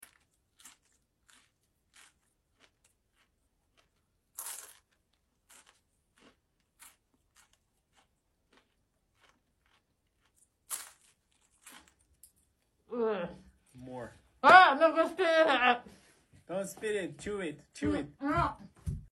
790 Foley chewing for the sound effects free download